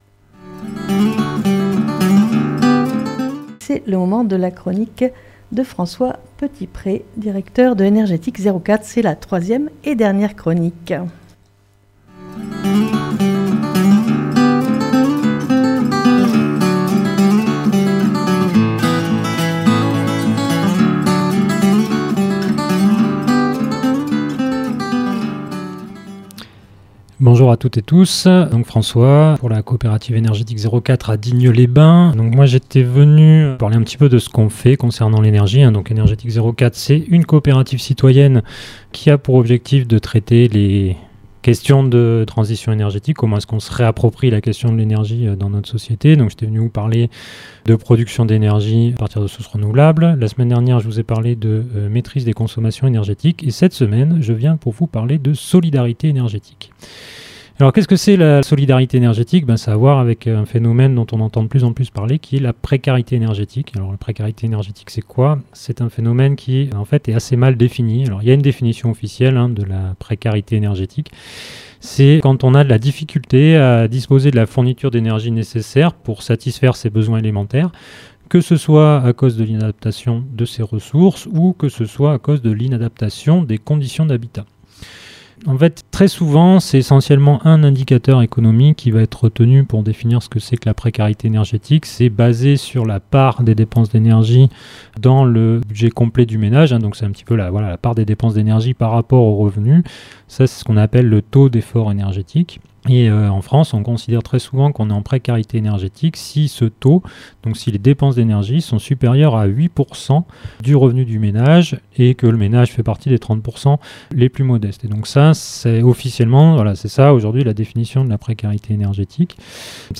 Lundi 21 Octobre 2024 3ème et dernière chronique ce lundi 21 octobre 2024 dans le magazine région Sud Est " A la bonne heure" sur Fréquence Mistral en direct de l'antenne de Digne les Bains porte sur le thème "La précarité énergétique".